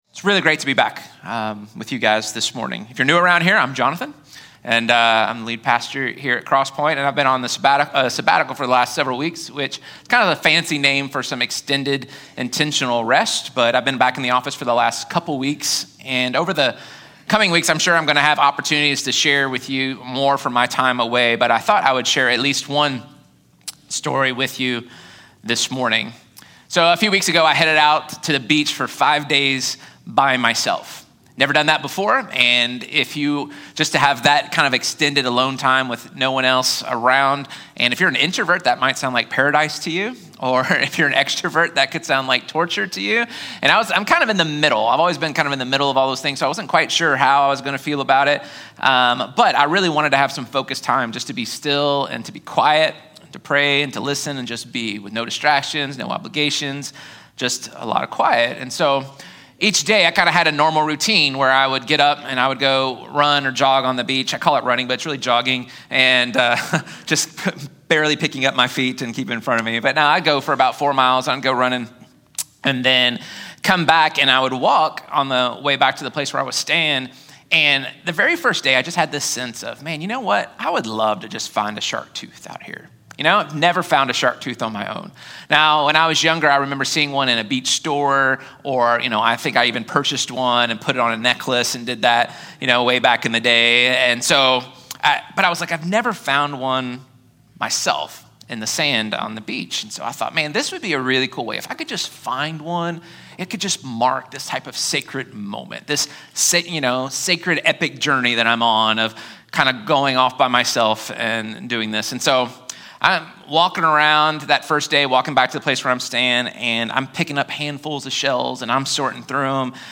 02. Sunday Message Series Kickoff – The Book of James: Context